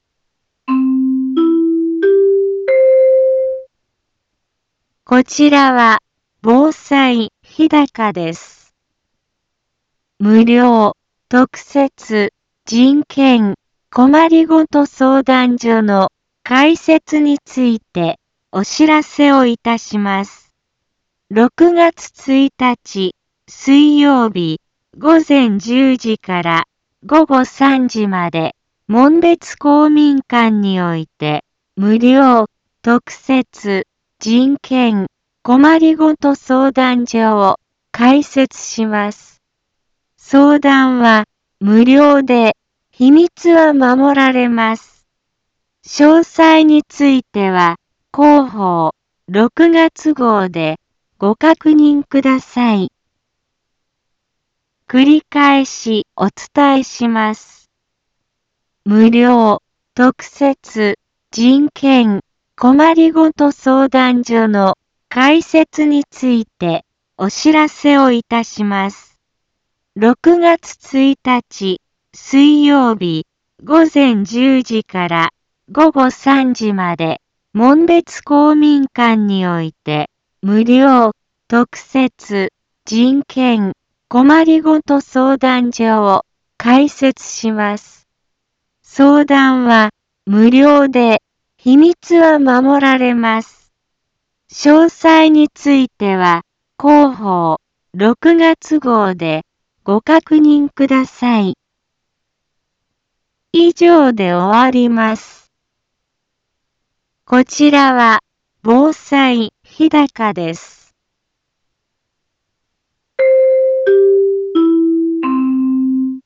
Back Home 一般放送情報 音声放送 再生 一般放送情報 登録日時：2022-05-24 10:04:05 タイトル：無料 特設人権・困りごと相談所開設のお知らせ インフォメーション：こちらは防災日高です。